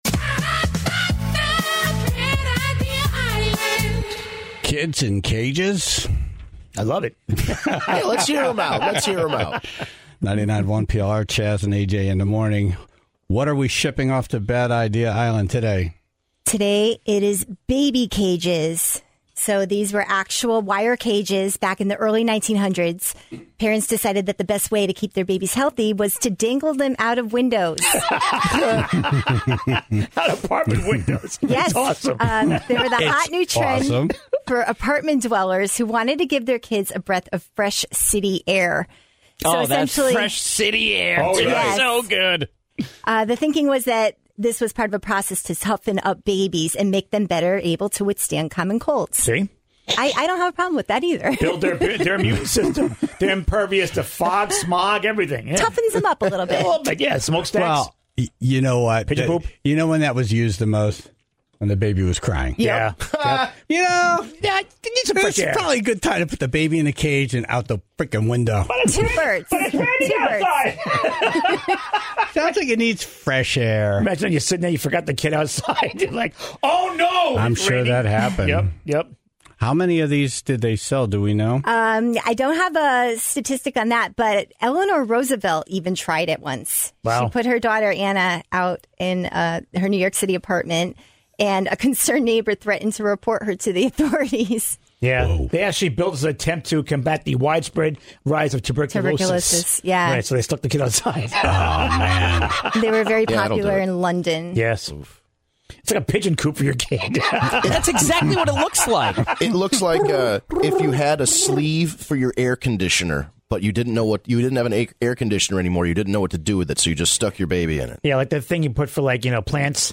Ratchets, padlocks, autographs, and circus mallets were all called in.